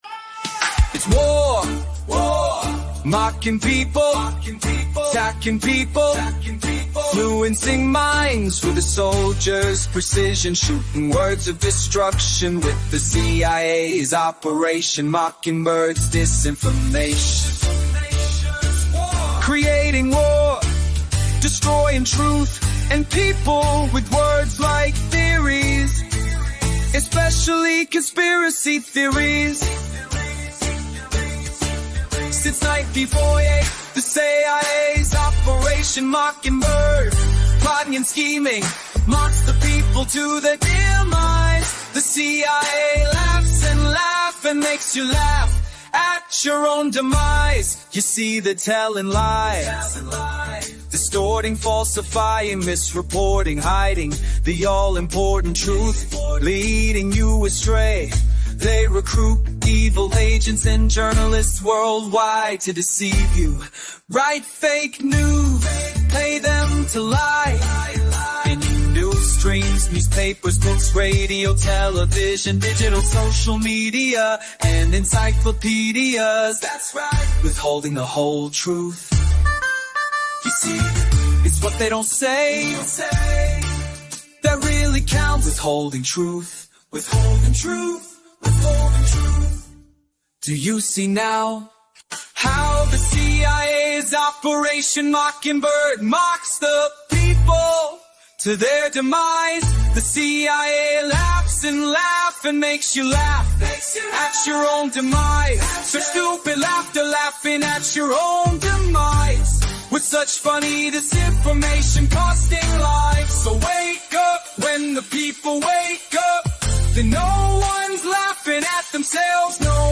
ONE OF MANY, FREE MP3 CUSTOM PRODUCED WITH AI - MUSIC PROTESTS SONGS!